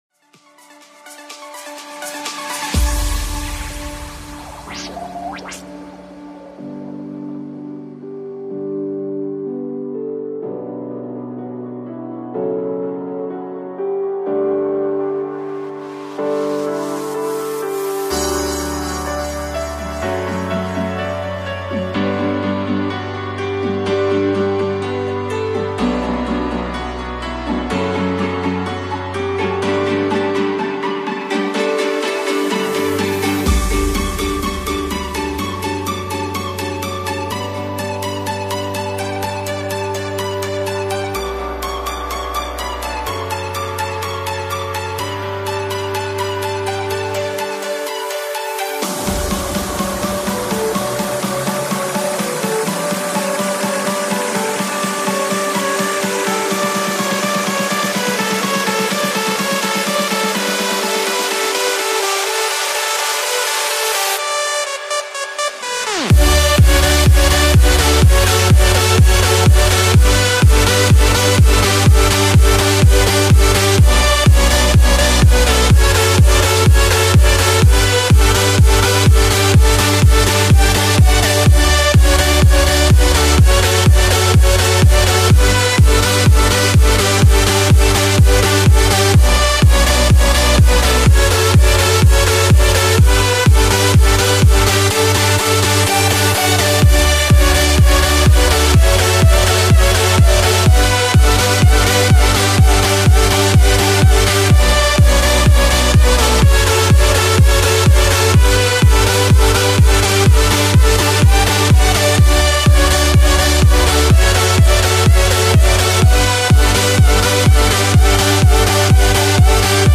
House, Euphoric, Happy, Hopeful, Energetic, Dreamy